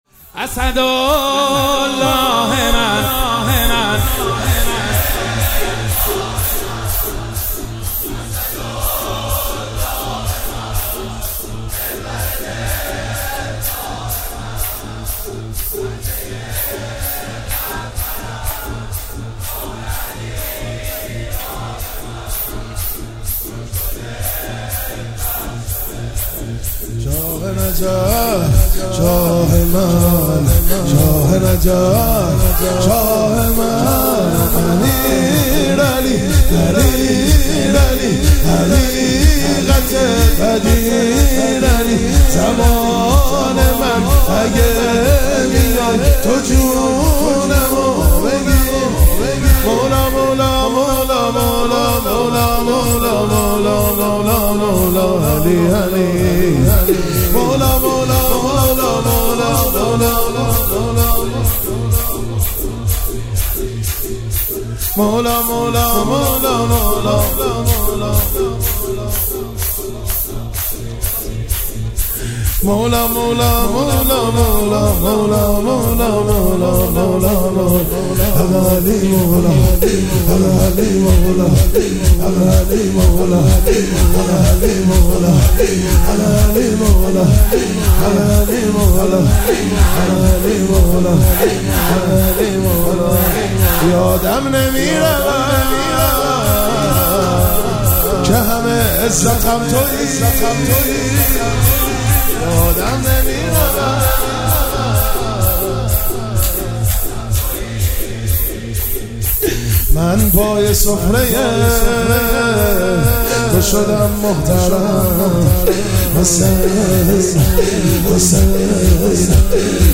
شب اول فاطمیه1402 4 آذر
شب اول فاطمیه1402 4 آذر ماه شور اسد الله من محمد حسین حدادیان